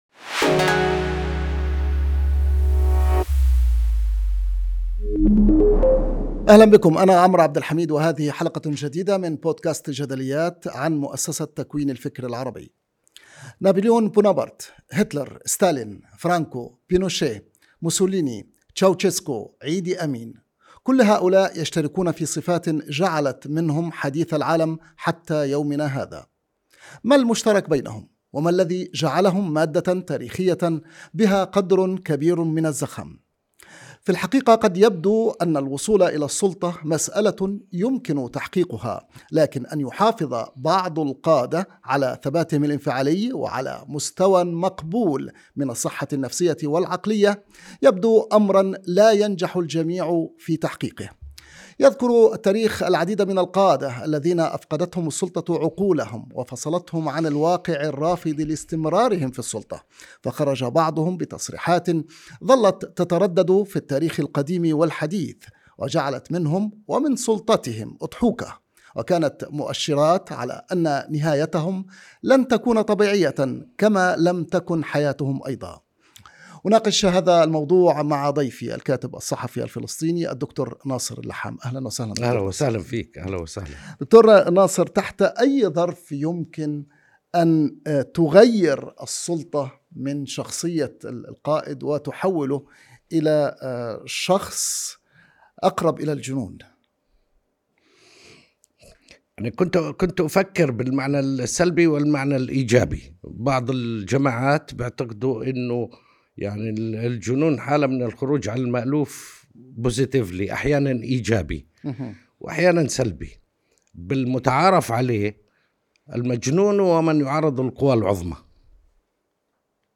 الإعلامي الفلسطيني الدكتور ناصر اللحام ضيف حلقة بودكاست جدليات مع عمرو عبد الحميد